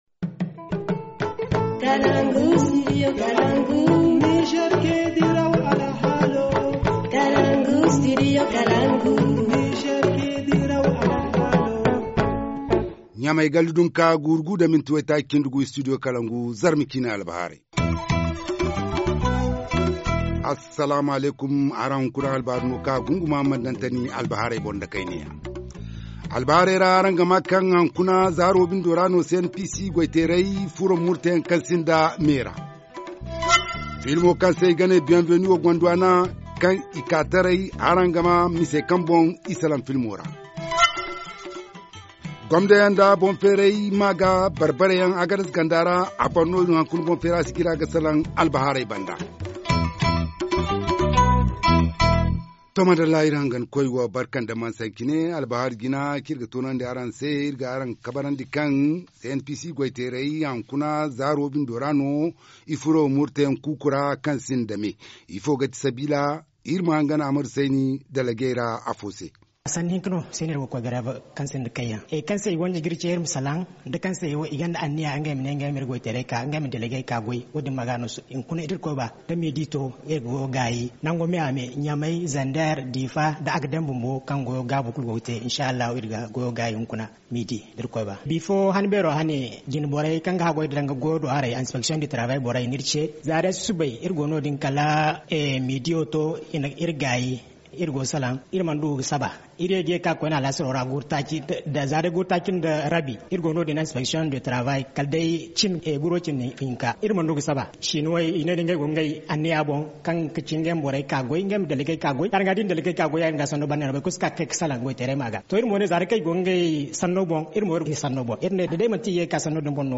1. Les travailleurs nationaux de la compagnie pétrolière CNPC sont en grève illimité depuis midi aujourd’hui. Un des délégués du personnel nous livre les motifs de ce débrayage.
2. Le film « Bienvenue au Gondwana » réalisé du fantaisiste nigérien Mamane a été projeté en avant-première à Abidjan le 8 décembre passé. L’un des acteurs de ce film nous en parle dans un instant.